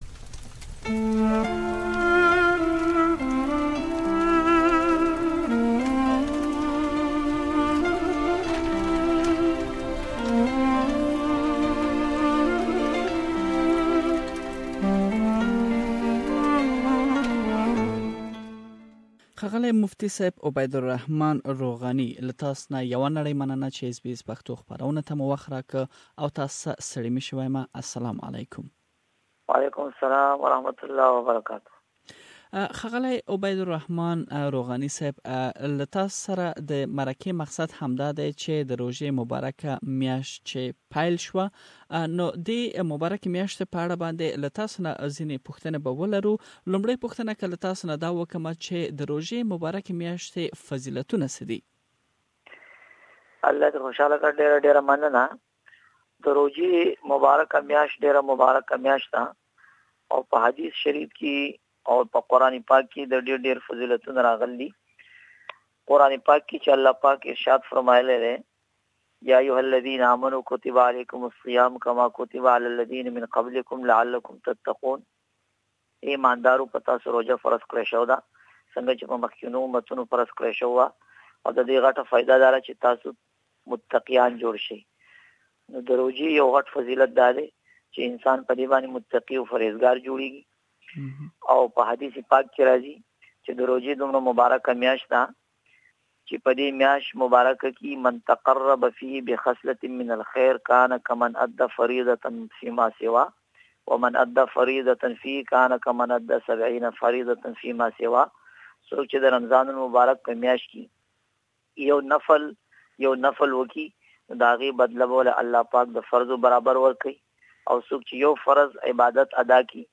This blessing month is also been celebrated in Australia with the message of Peace, prayer and patience. I have interviewed